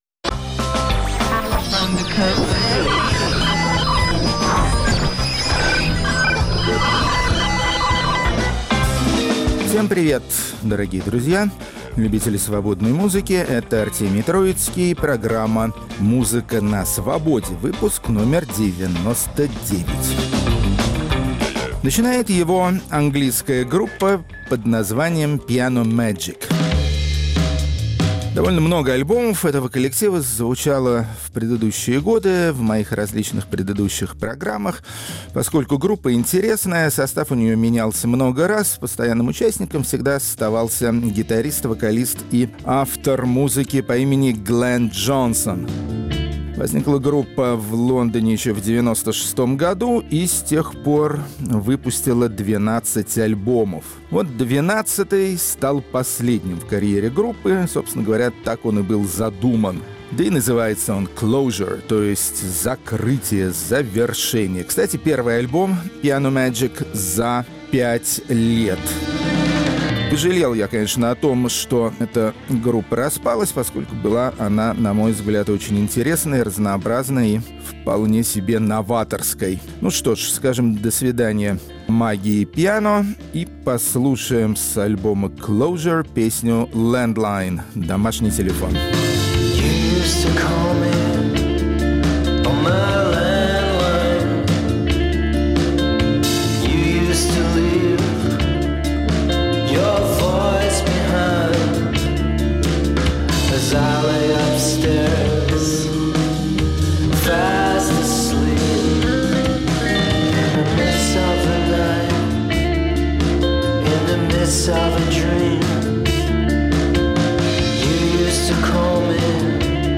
Музыка на Свободе. 19 января, 2019 Хедлайнеры девяносто девятого выпуска программы "Музыка на Свободе" – российские группы альтернативного рока. Рок-критик Артемий Троицкий ищет и находит причины для новой и новой пропаганды творчества некоммерческих отечественных исполнителей, хотя его за это и критикуют.